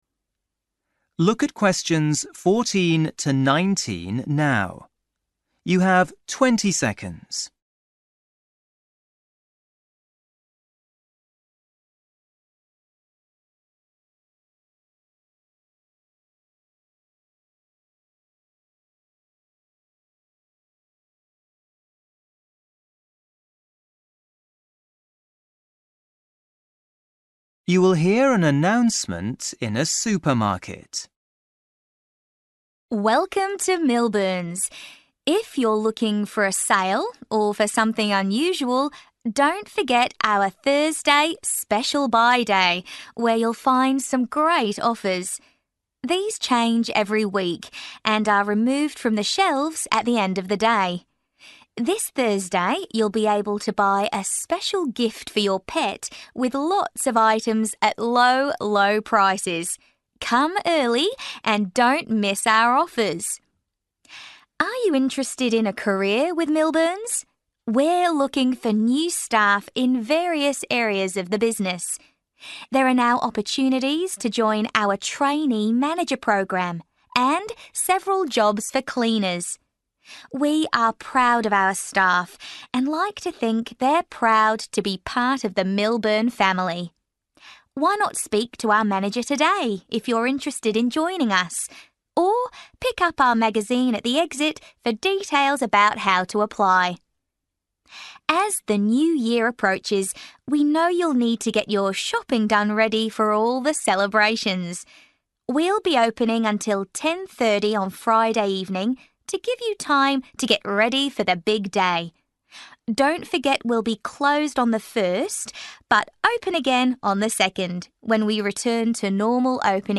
You will hear an announcement in a supermarket.